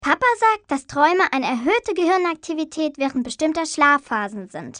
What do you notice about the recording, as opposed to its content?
Effective Media localised Ruff & Tumble's lip-sync video sequences which almost constitute an independent film, as well as all in-game texts.